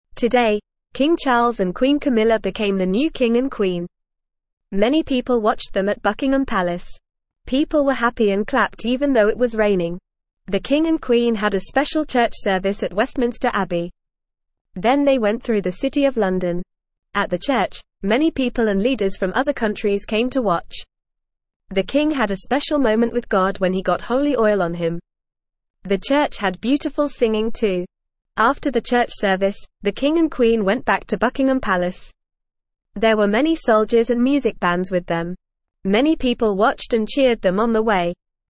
Fast
English-Newsroom-Beginner-FAST-Reading-New-King-and-Queen-Celebration-Day.mp3